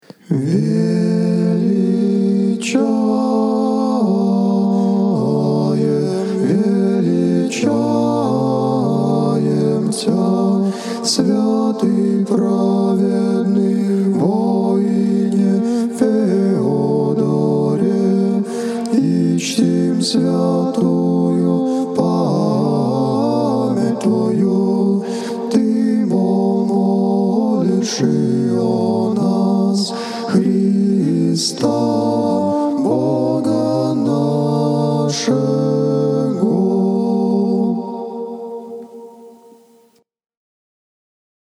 Величание
Velichanie.mp3